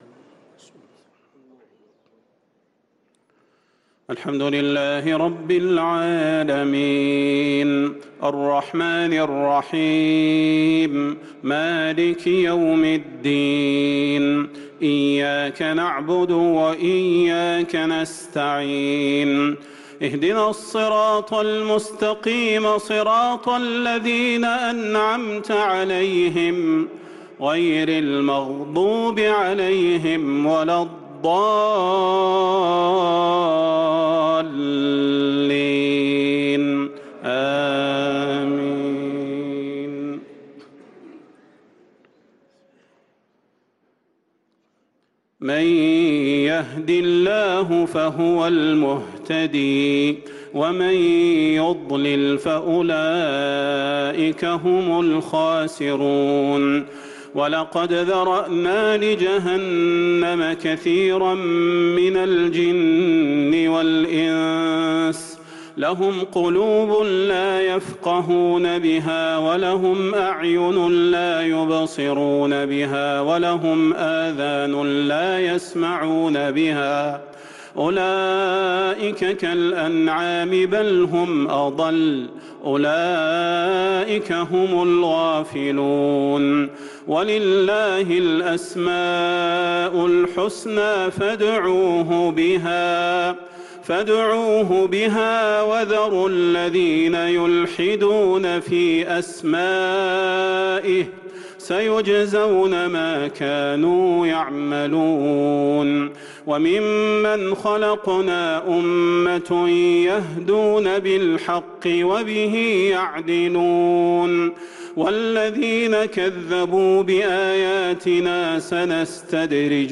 صلاة العشاء للقارئ صلاح البدير 19 ربيع الأول 1444 هـ
تِلَاوَات الْحَرَمَيْن .